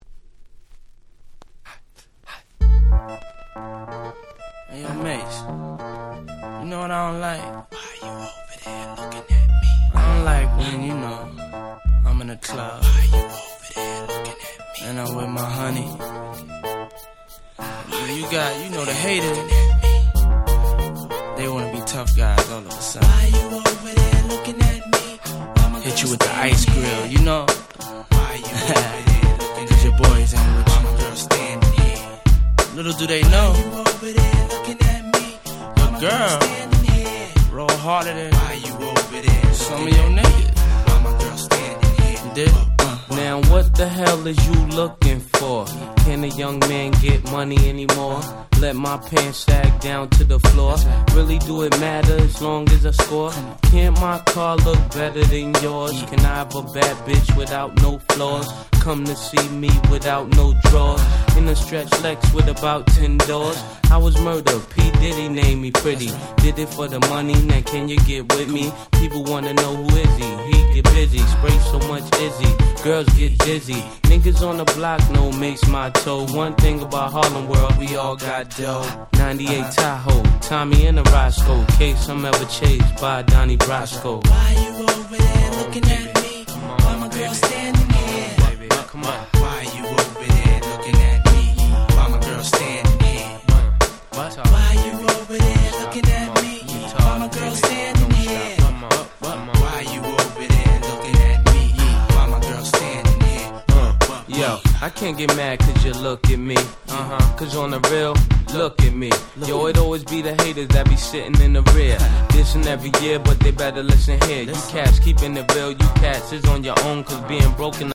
98' Super Hit Hip Hop !!